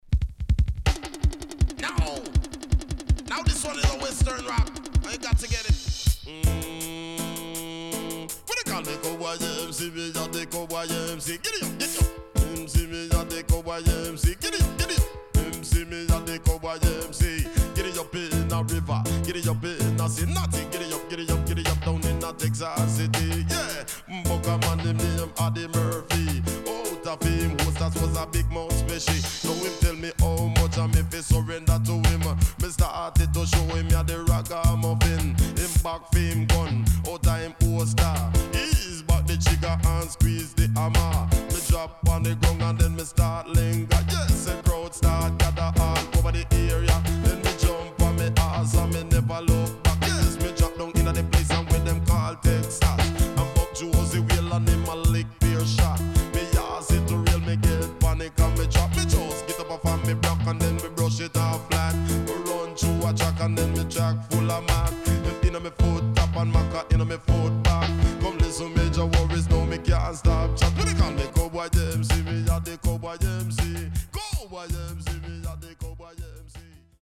HOME > DISCO45 [DANCEHALL]